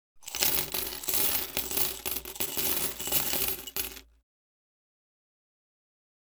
household
Coins Being Poured on Hard Surface